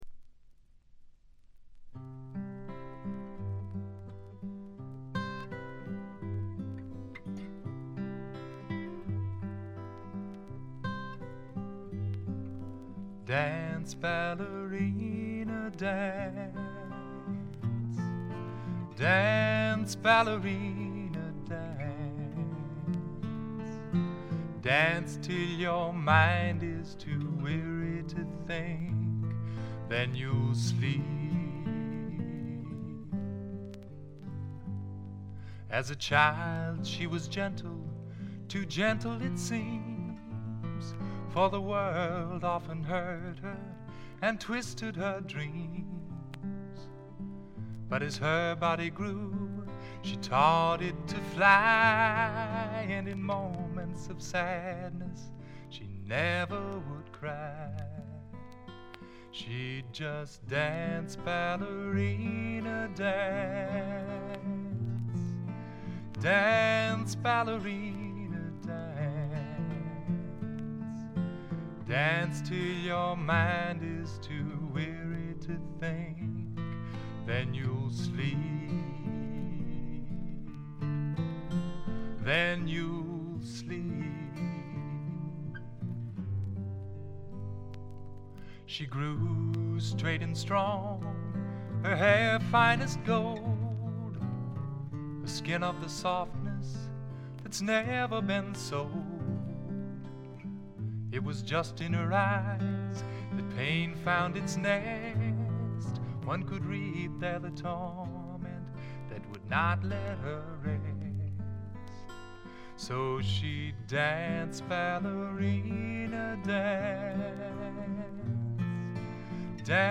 軽微なチリプチ。散発的なプツ音少し。
シンプルなバックに支えられて、おだやかなヴォーカルと佳曲が並ぶ理想的なアルバム。
試聴曲は現品からの取り込み音源です。